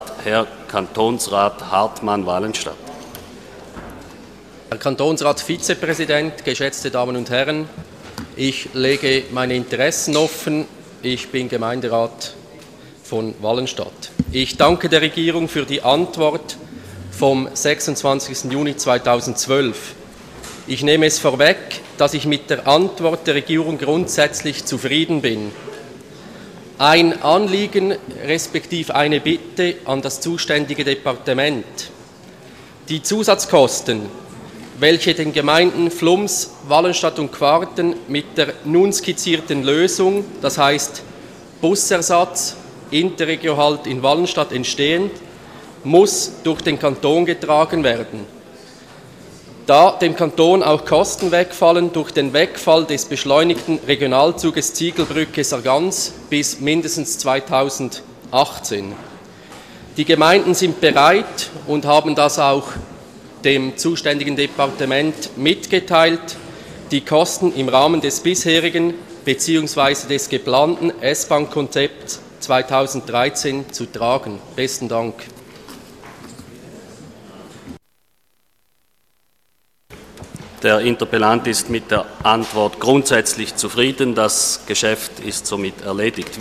25.9.2012Wortmeldung
Session des Kantonsrates vom 24. und 25. September 2012